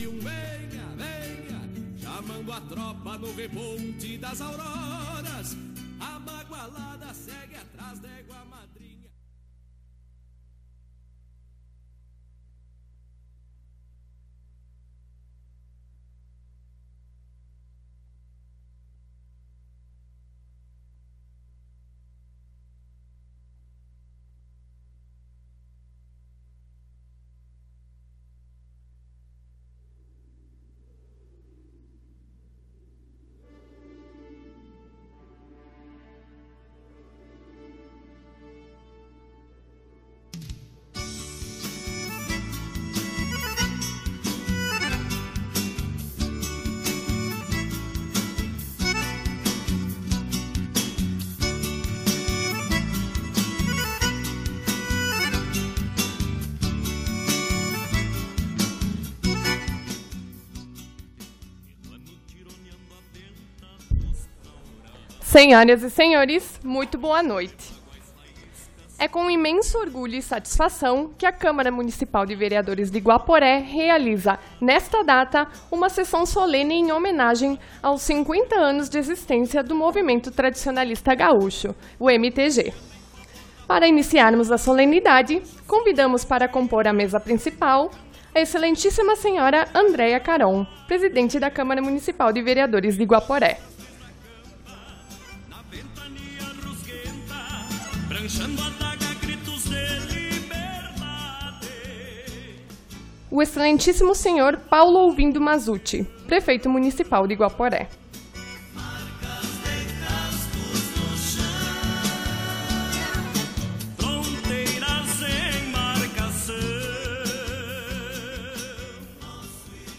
Sessão Solene do dia 19 de Agosto de 2016 em Homenagem aos 50 anos MTG